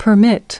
permit-verb.mp3